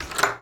pgs/Assets/Audio/Doors/door_lock_open_04.wav
door_lock_open_04.wav